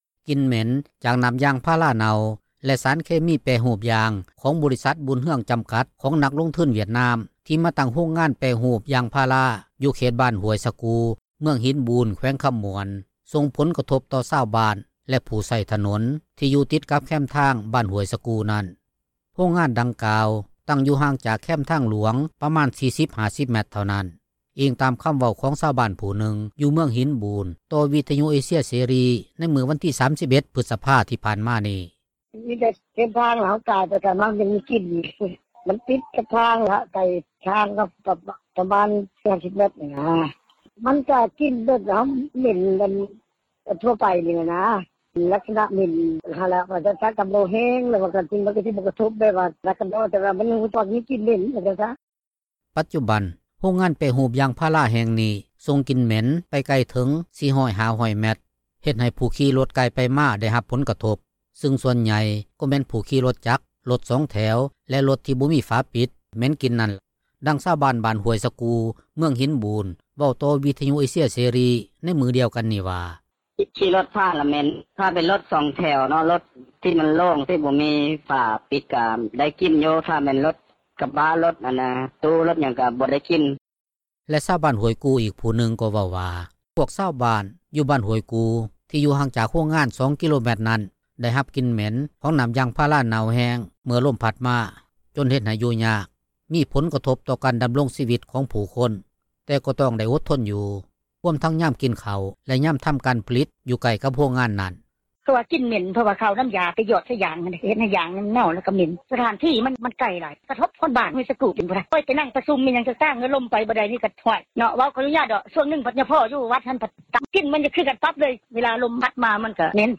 ດັ່ງຊາວບ້ານບ້ານຫ້ວຍສະກູ່ ເມືອງຫິນບູນ ເວົ້າຕໍ່ວິທຍຸເອເຊັຽເສຣີ ໃນມື້ດຽວກັນນີ້ວ່າ: